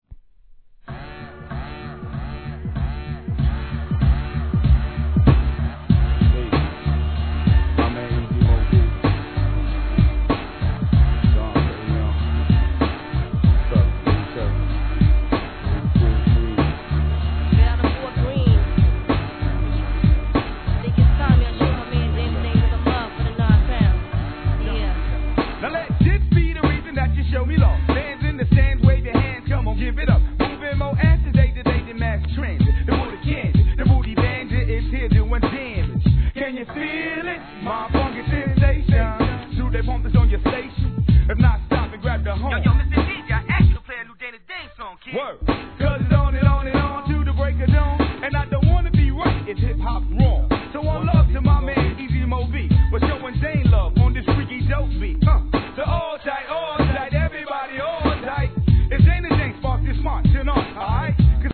HIP HOP/R&B
爽快なFUNKトラックにコーラスが絡む1995年G人気作!!